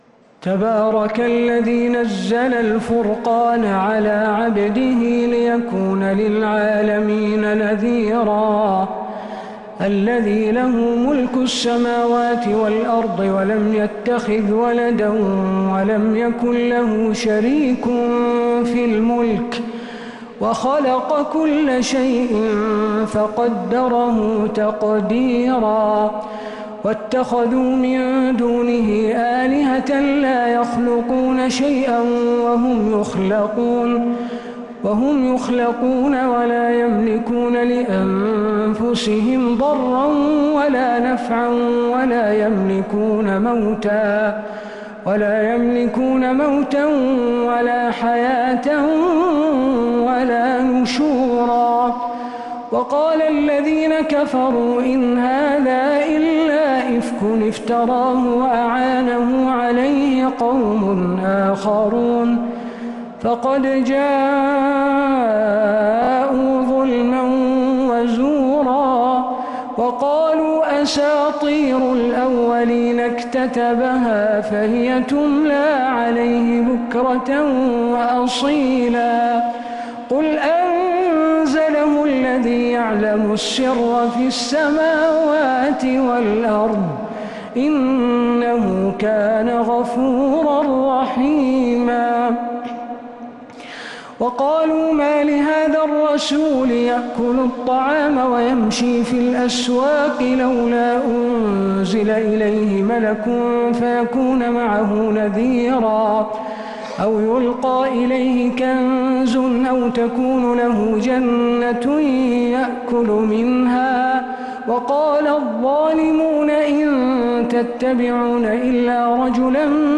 سورة الفرقان كاملة صلاة الخسوف 15 ربيع الأول 1447هـ.